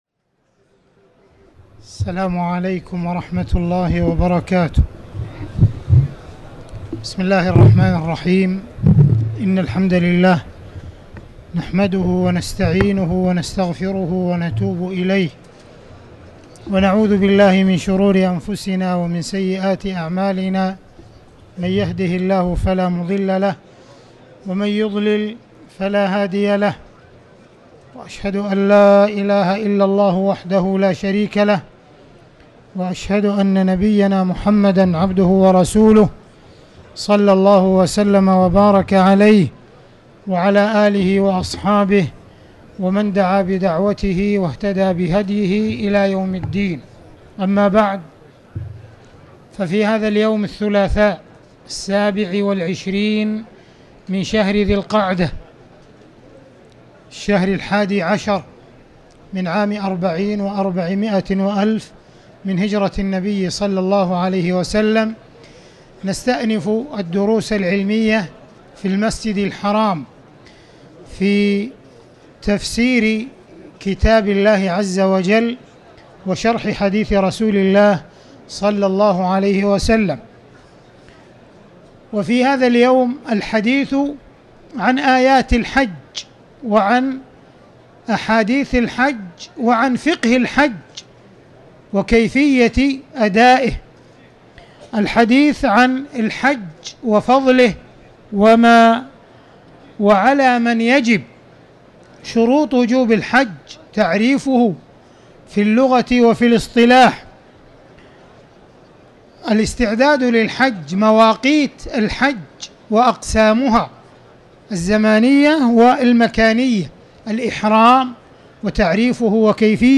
تاريخ النشر ٢٧ ذو القعدة ١٤٤٠ هـ المكان: المسجد الحرام الشيخ: معالي الشيخ أ.د. عبدالرحمن بن عبدالعزيز السديس معالي الشيخ أ.د. عبدالرحمن بن عبدالعزيز السديس آيات وأحاديث الحج